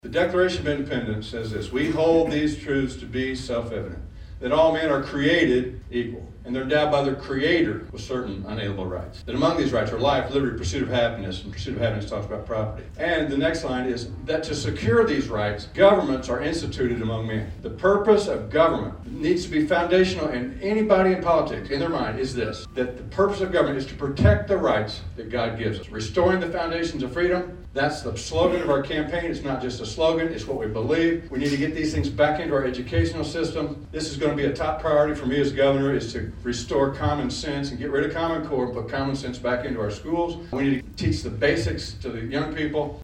Carroll County Republican Committee Hosts Candidates Running For Governor In GOP Forum Wednesday Evening
Not an empty seat could be found at the Santa Maria Winery, with attendees having a chance to socialize, hear directly from the GOP’s hopefuls, and having small group discussions with the contenders.
gop-forum-1.mp3